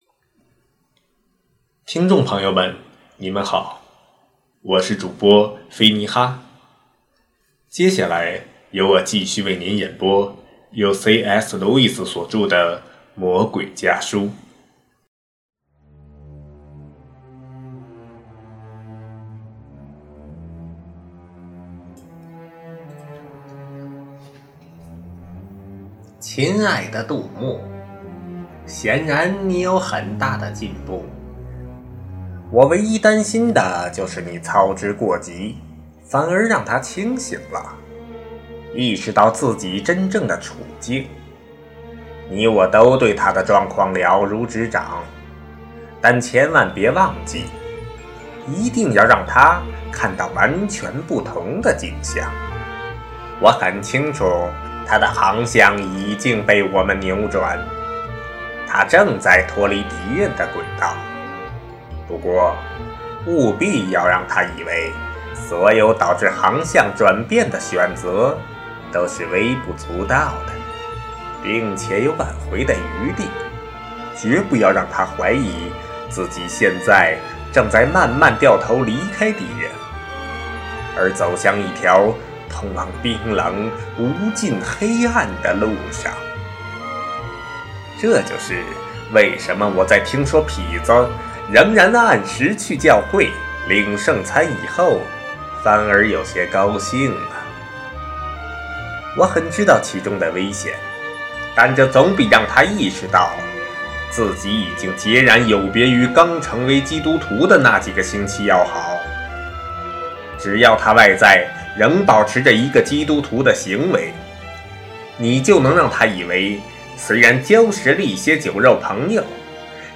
首页 > 有声书 | 灵性生活 | 魔鬼家书 > 魔鬼家书：第十二封书信